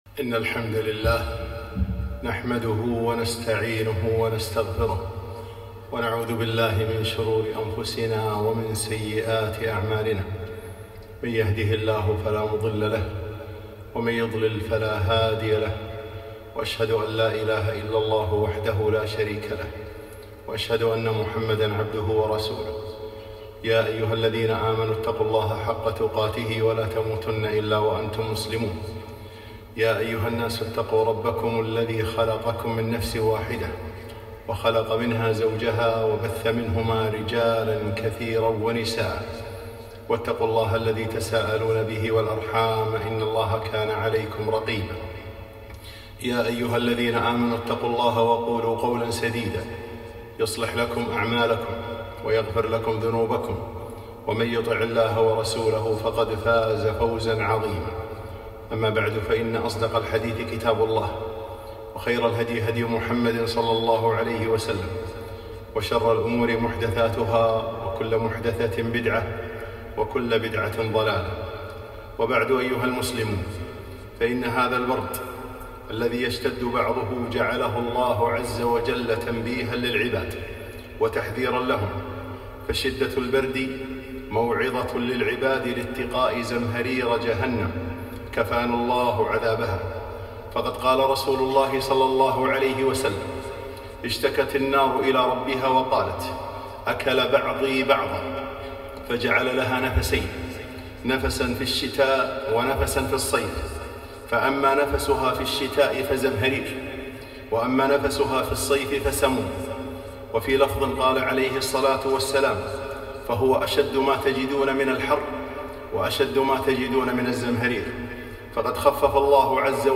خطبة - التحذير من البرد